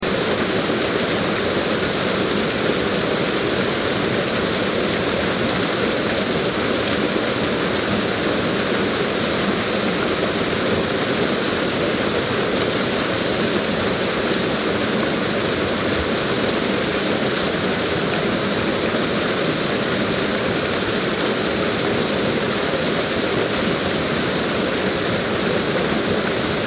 دانلود صدای آبشار 1 از ساعد نیوز با لینک مستقیم و کیفیت بالا
جلوه های صوتی